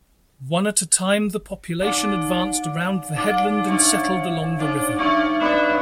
Tag: 添加 0分贝 噪声